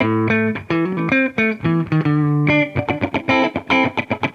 Index of /musicradar/sampled-funk-soul-samples/110bpm/Guitar
SSF_TeleGuitarProc1_110A.wav